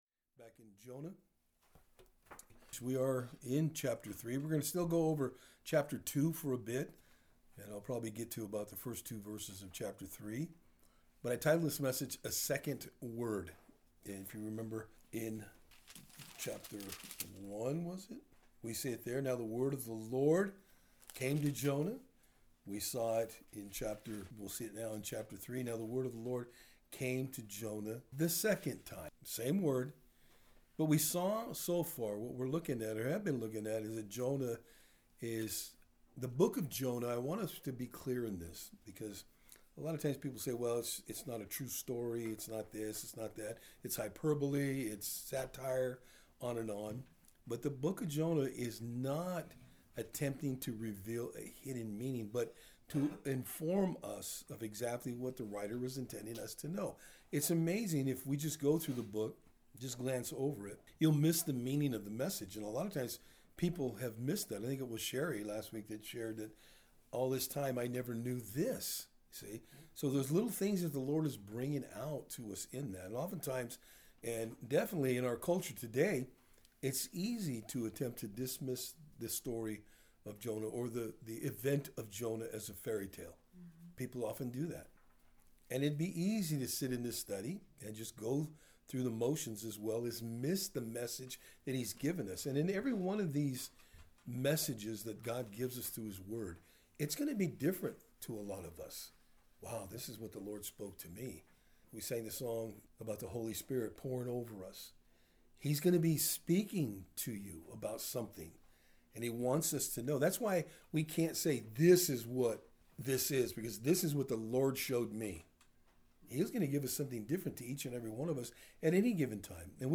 Jonah 2:7-3:2 Service Type: Thursday Afternoon In our study today we will be looking at God does not relent on Jonahs disobedience.